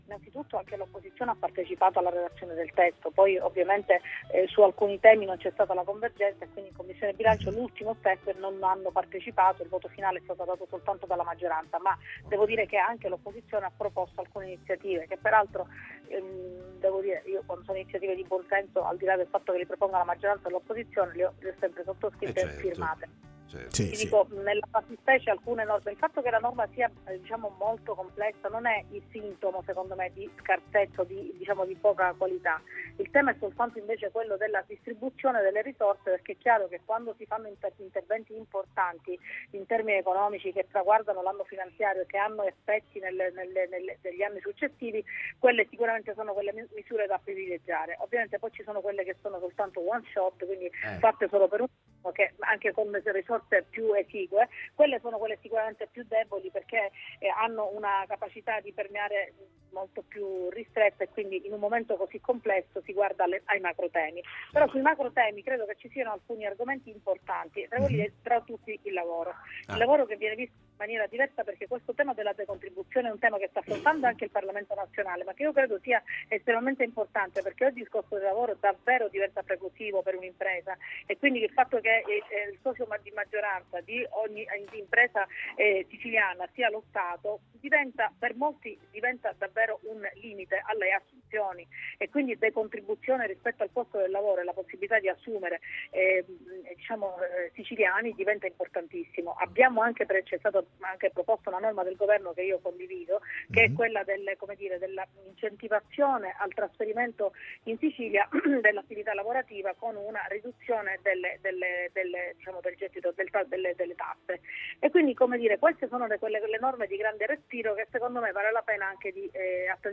Marianna Caronia al Time Magazine parla di finanziaria Interviste Time Magazine 11/12/2025 12:00:00 AM / Time Magazine Condividi: L’On.